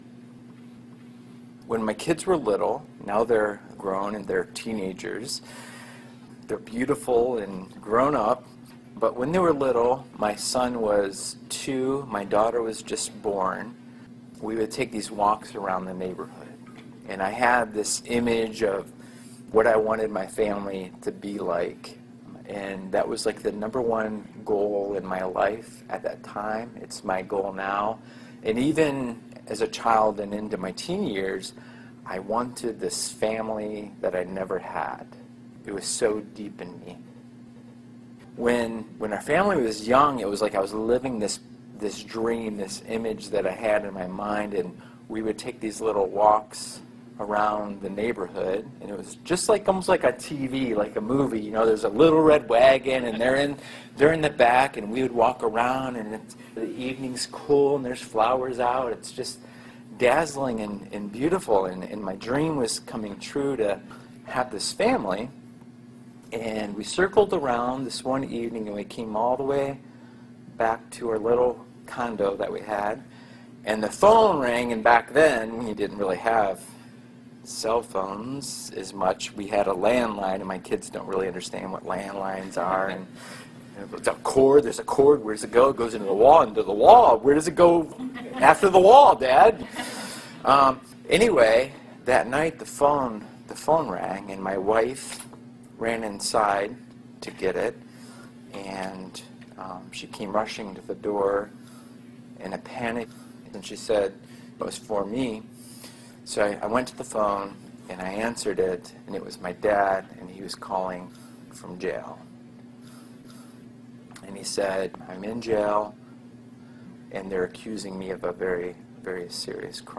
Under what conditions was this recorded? This post is a recording of the recent talk I gave at Northridge Church.